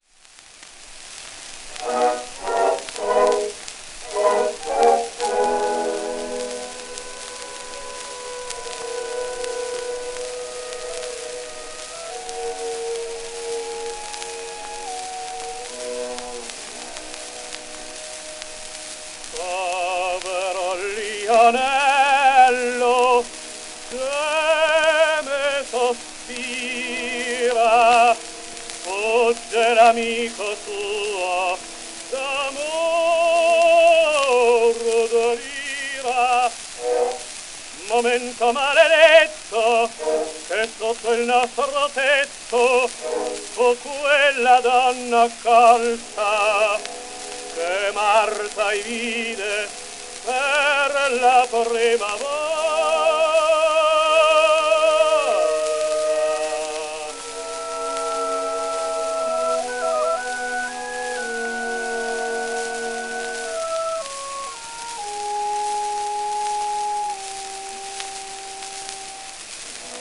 w/オーケストラ
12インチ片面盤
1906年頃録音
旧 旧吹込みの略、電気録音以前の機械式録音盤（ラッパ吹込み）